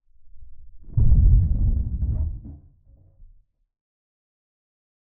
Bang2.ogg